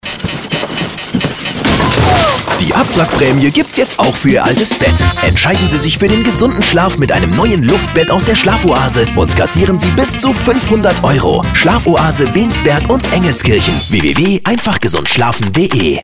Radiowerbung Luftbett: